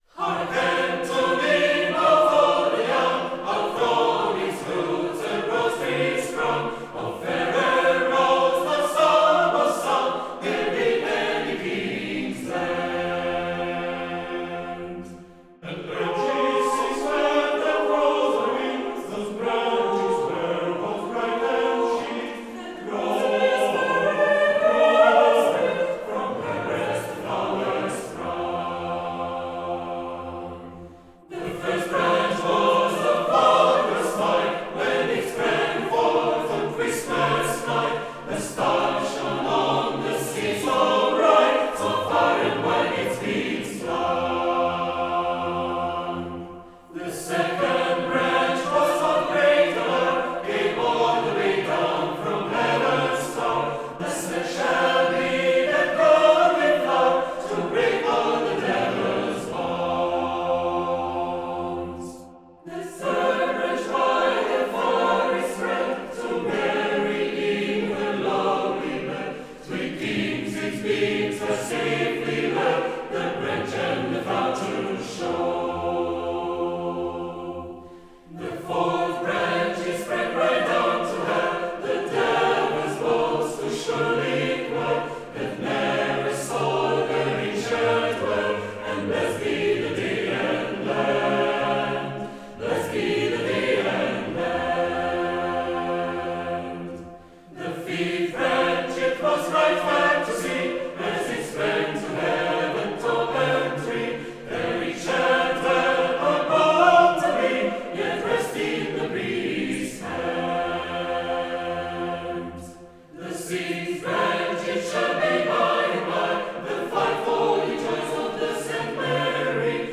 Mixed Choir Tempo - Medium Slow BPM - 69